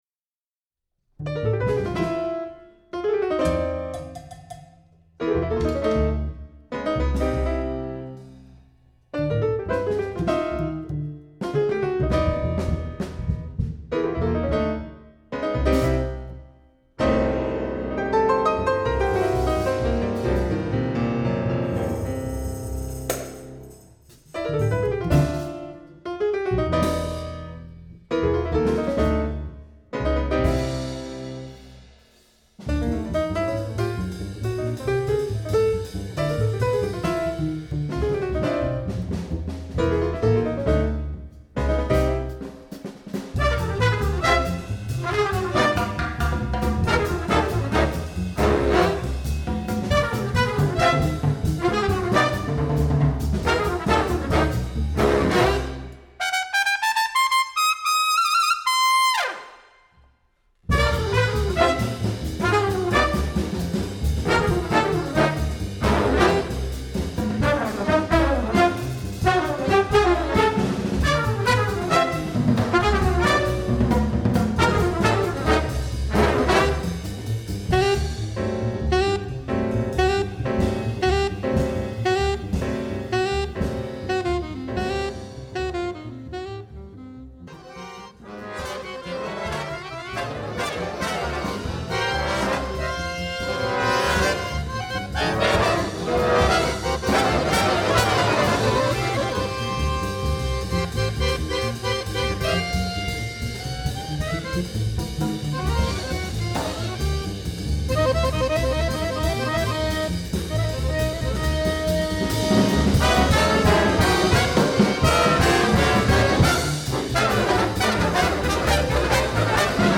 Store/Music/Big Band Charts/ORIGINALS/PORTRAIT
Lead Trumpet Range: high F# (optional)
Solos: open